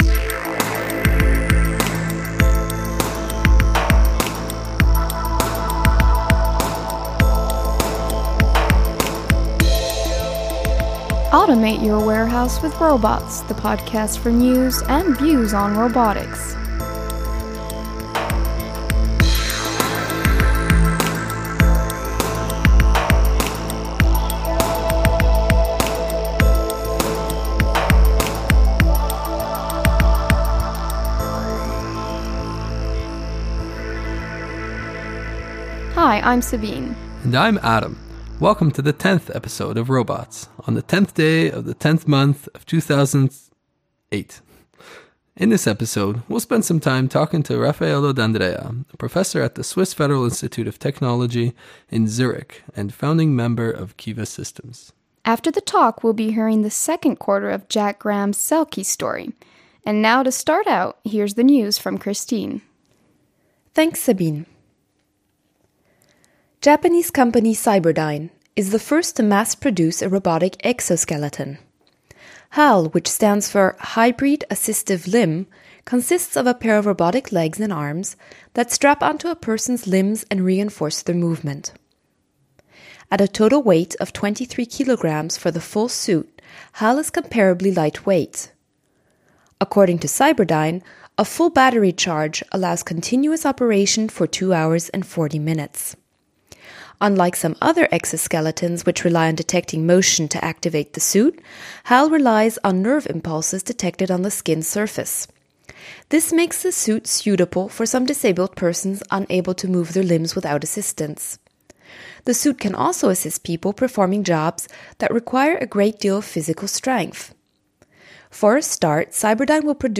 View and post comments on this episode in the forum tags: podcast , Swarming Podcast team The ROBOTS Podcast brings you the latest news and views in robotics through its bi-weekly interviews with leaders in the fi